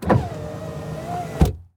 windowpart2.ogg